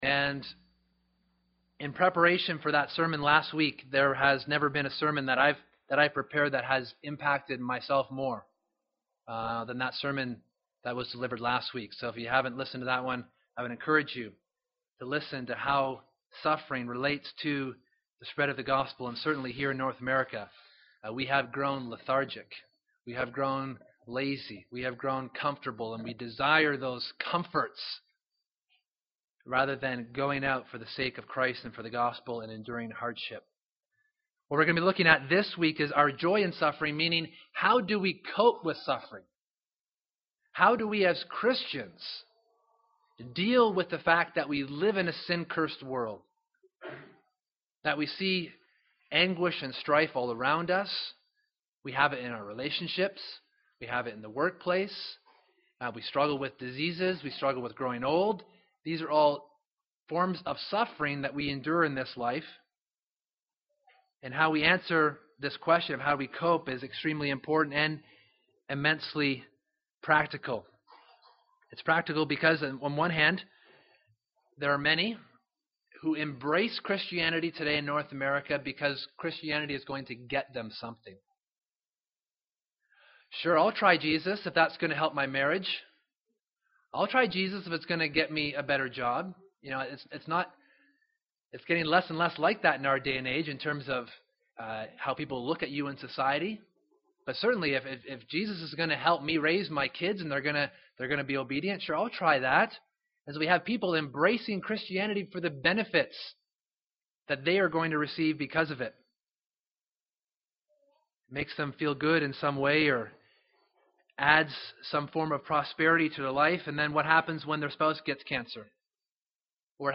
( Sunday AM )